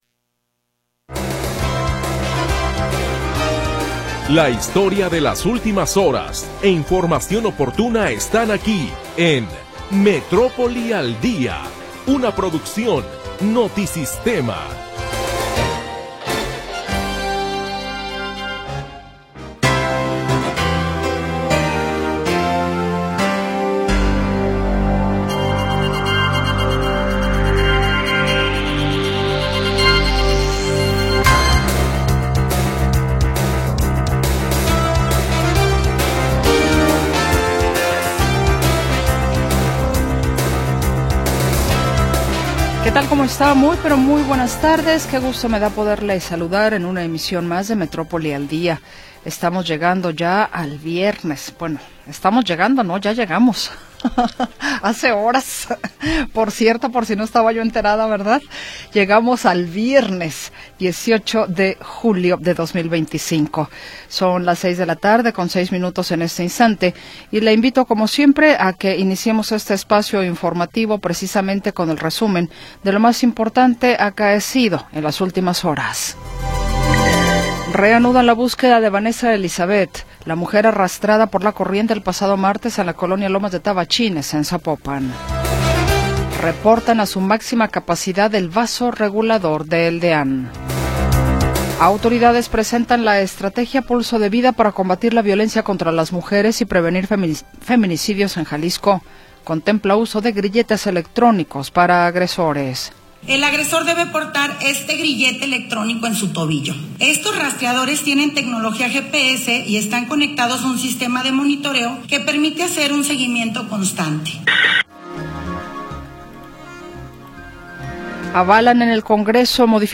Primera hora del programa transmitido el 18 de Julio de 2025.